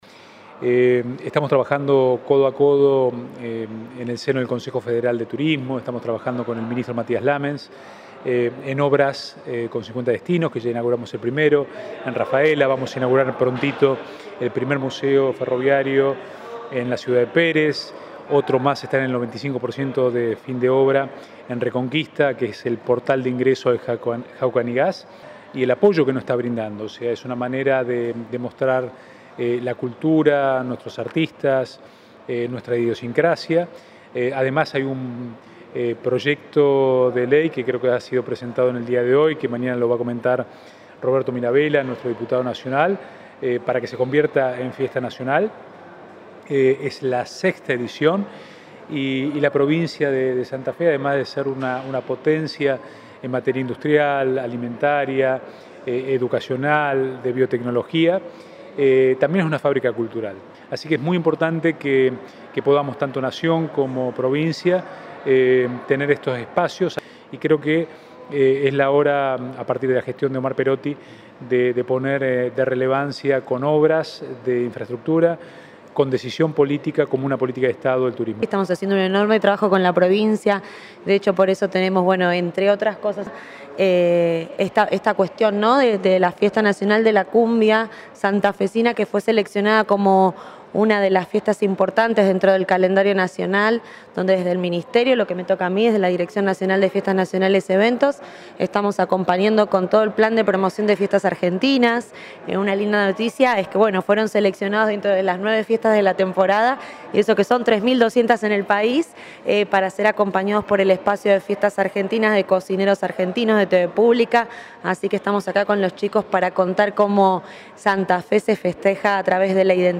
El secretario de Turismo, Alejandro Grandinetti.